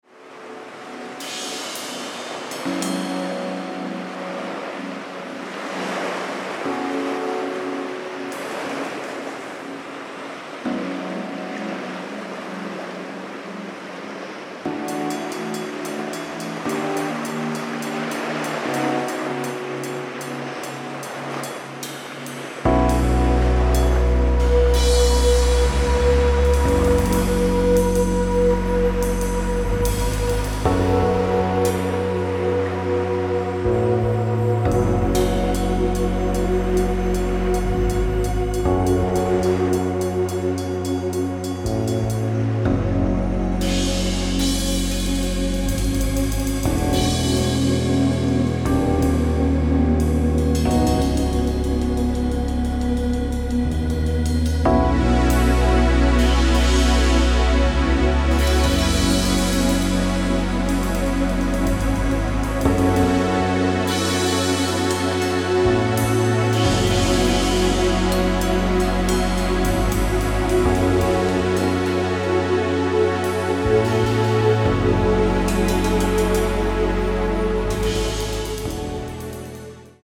stripped down reprise